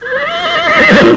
סוס.mp3